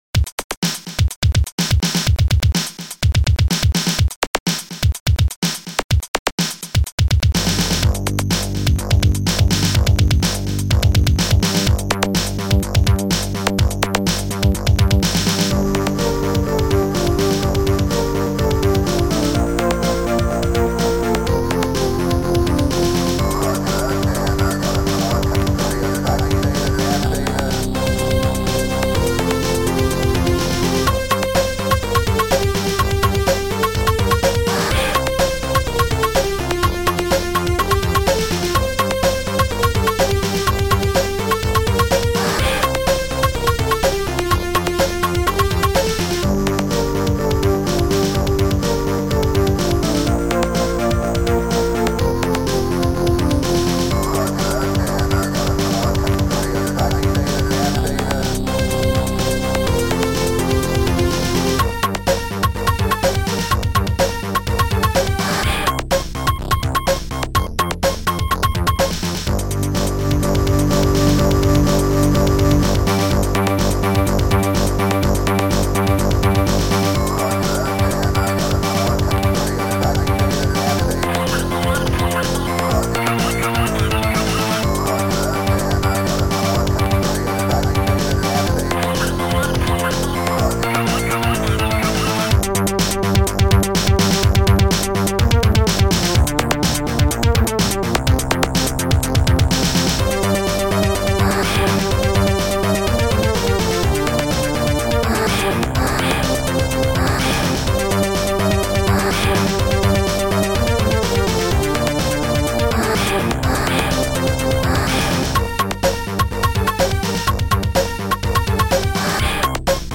Sound Format: Soundtracker 15 Samples